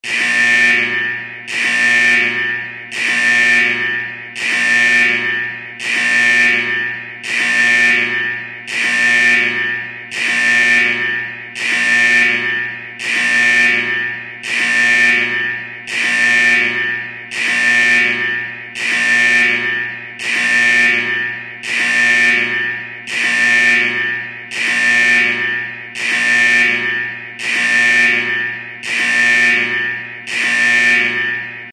Alarm sound in a factory during a fire
• Category: Fire alarm
• Quality: High